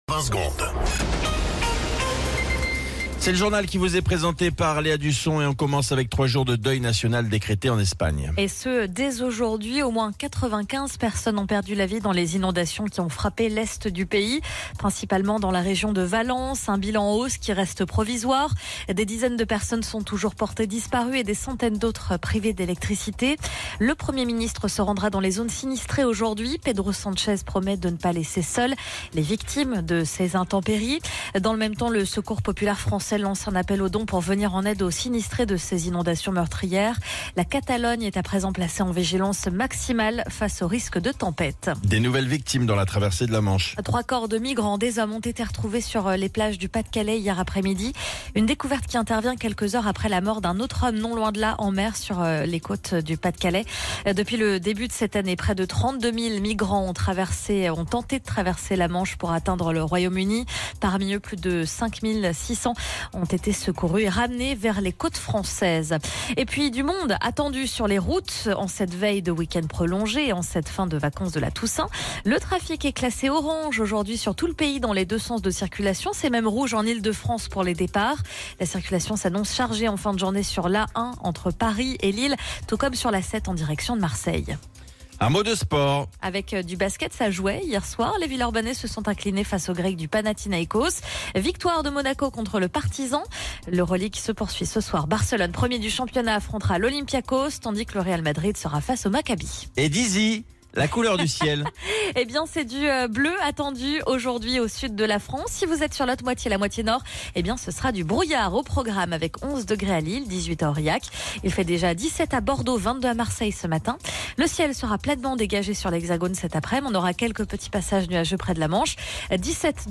Flash Info National 31 Octobre 2024 Du 31/10/2024 à 07h10 .